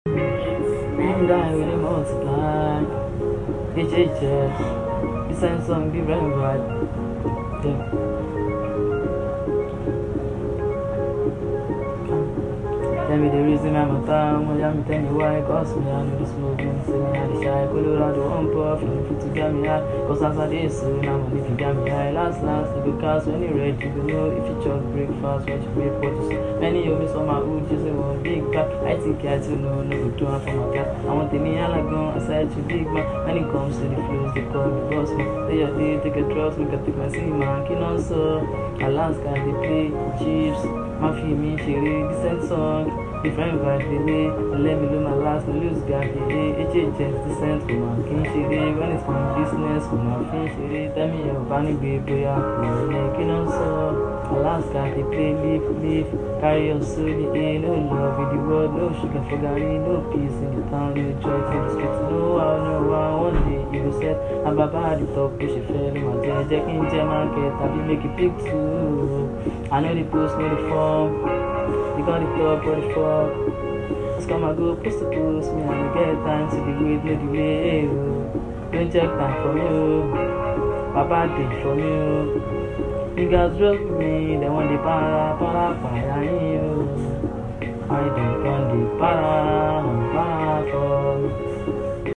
Not just a Freestyle it’s inspirational vibez